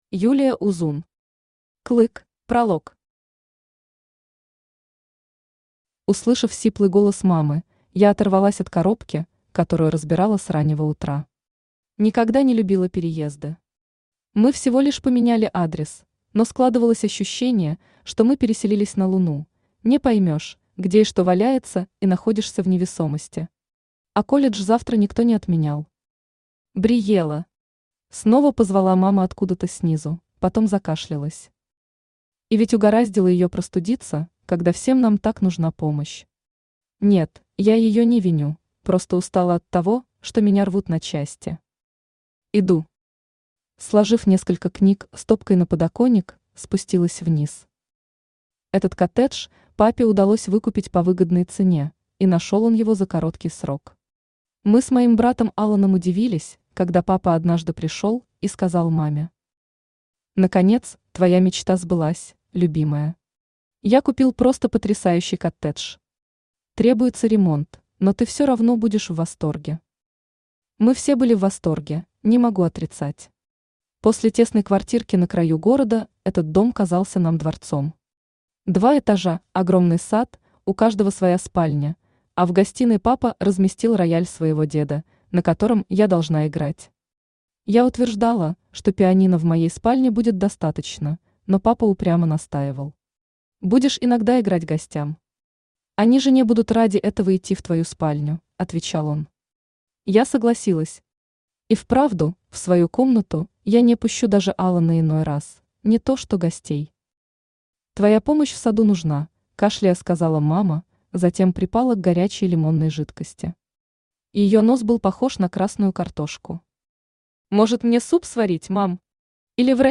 Аудиокнига Клык | Библиотека аудиокниг
Aудиокнига Клык Автор Юлия Узун Читает аудиокнигу Авточтец ЛитРес.